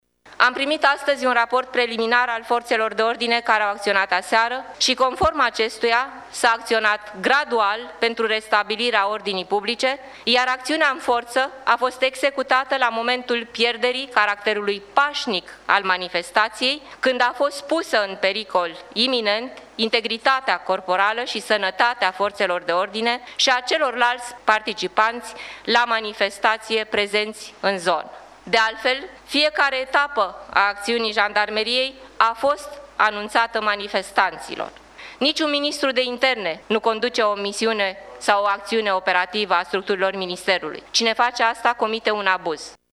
La Spitalul Floreasca din Capitală a ajuns şi ministrul de interne, Carmen Dan. Aceasta a explicat intervenția de aseară a jandarmilor din dispozitivul aflat aseară în Piața Victoriei: